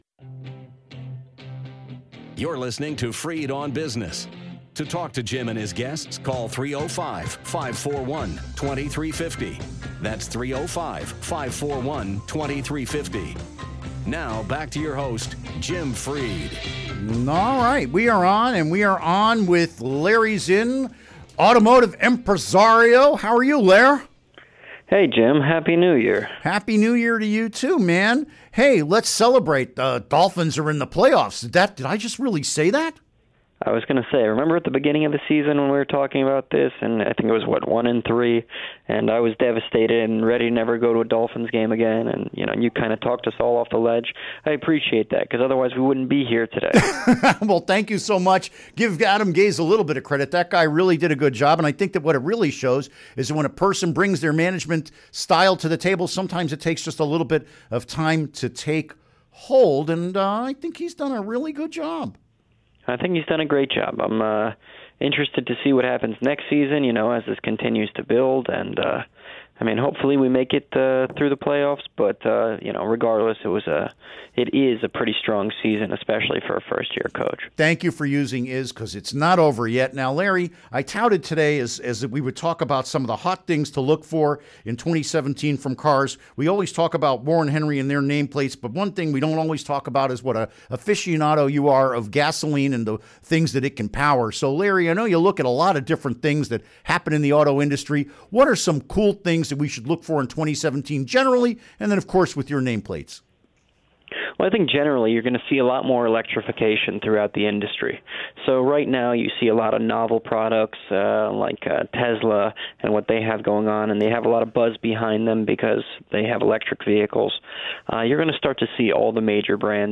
Interview Segment Episode 401: 01-05-17 Download Now!